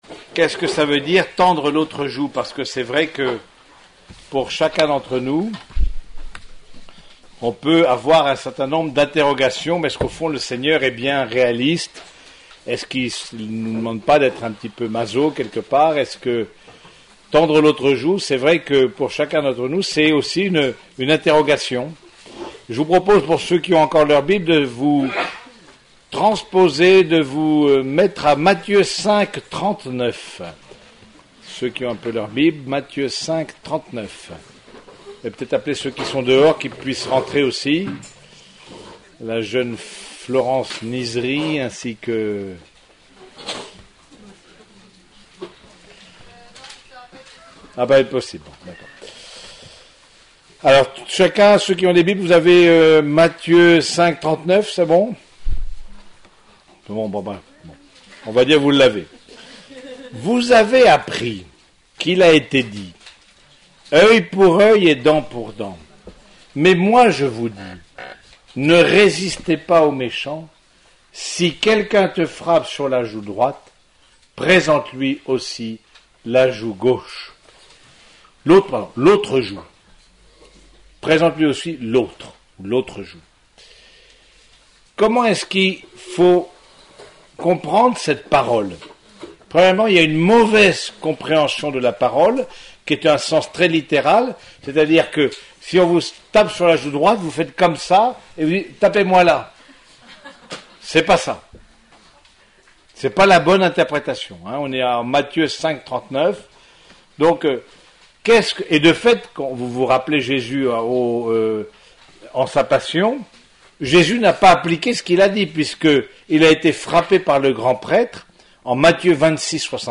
Dans cet article, vous pourrez ré-écouter quatre enseignements donnés lors du week-end Jeunes professionnels du 14 au 16 mars 2014.